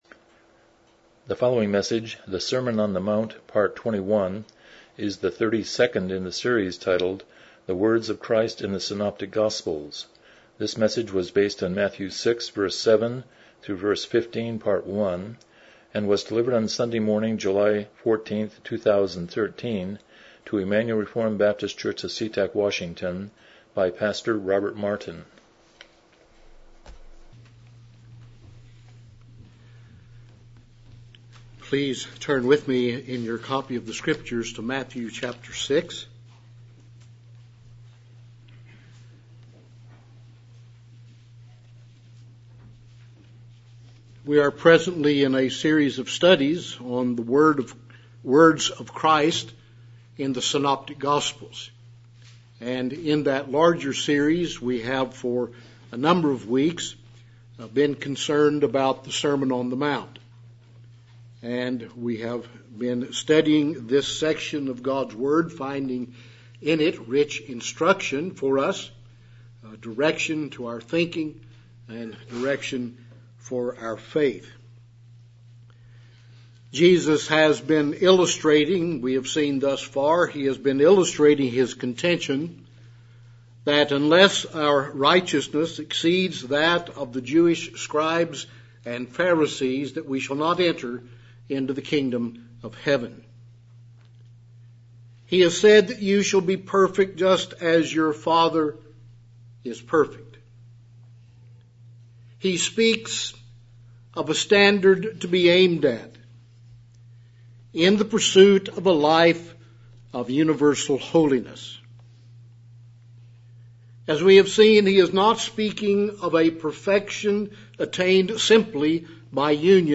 Passage: Matthew 6:7-15 Service Type: Morning Worship